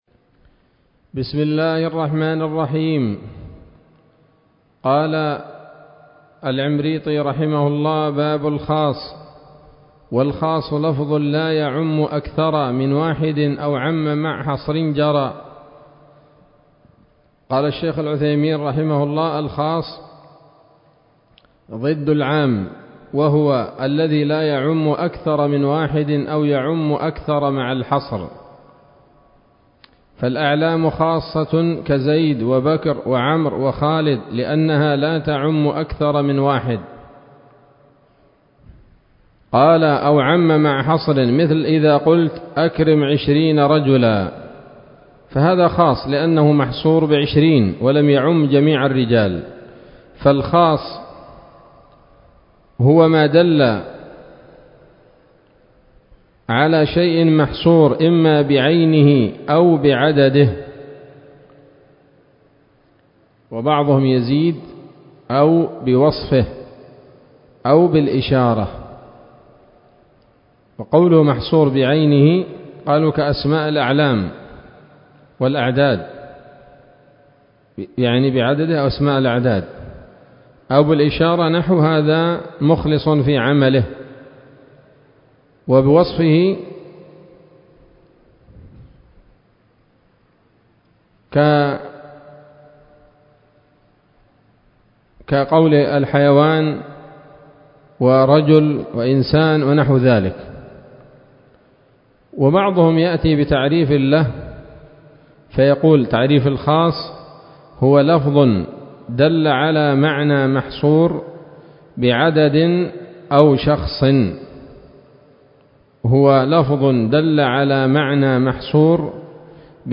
الدرس الأربعون من شرح نظم الورقات للعلامة العثيمين رحمه الله تعالى